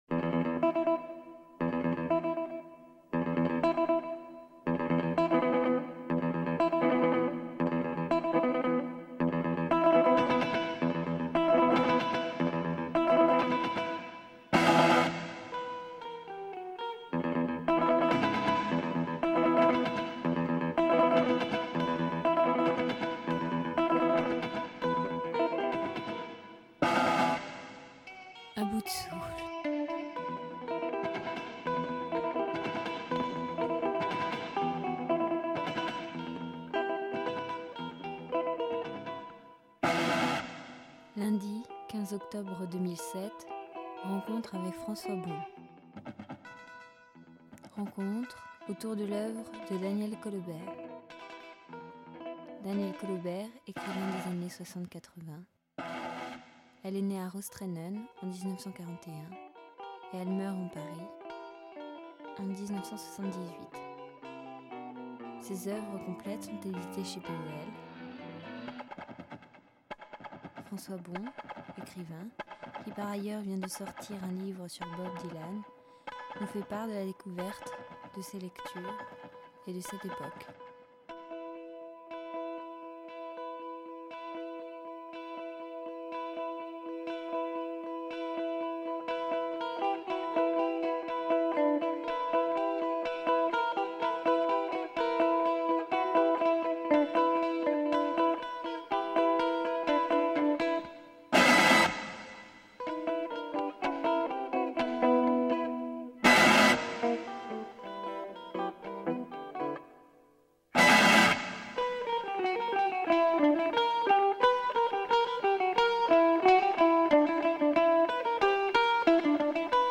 Rencontre avec Fran�ois Bon